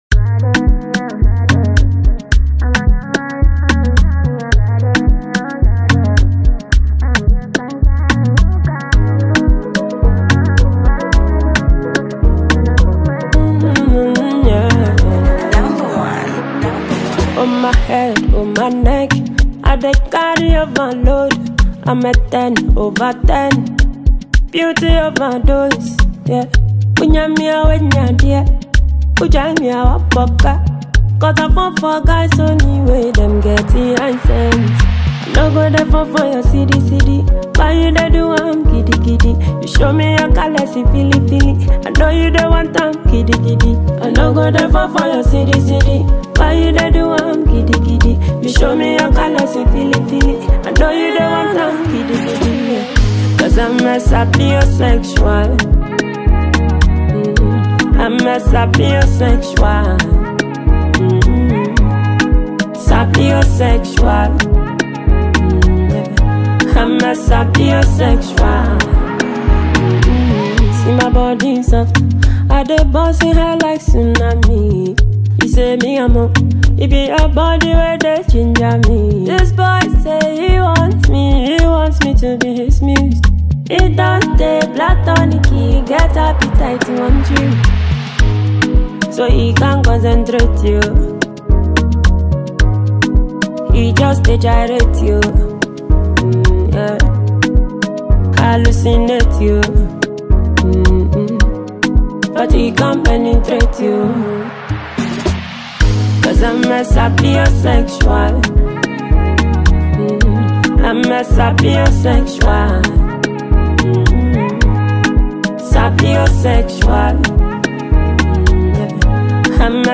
Another catchy tune
Ghanaing singer, songwriter